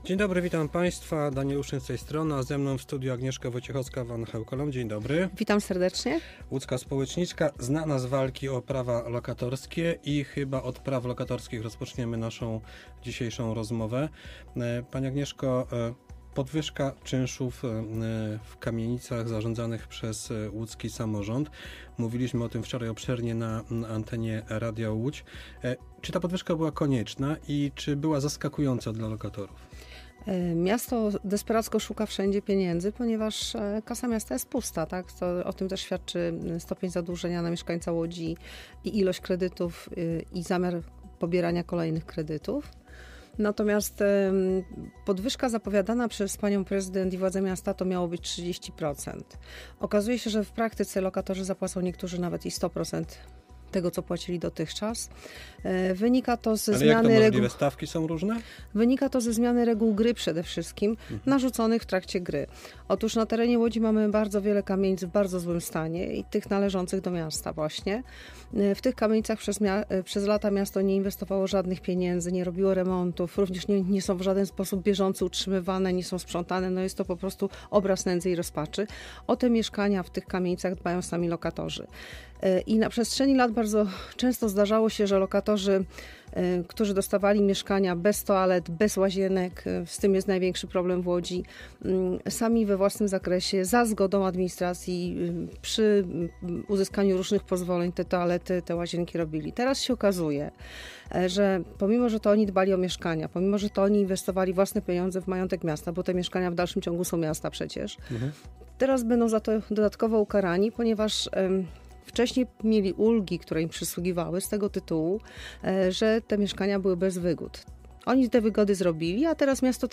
Gościem po 8. była społeczniczka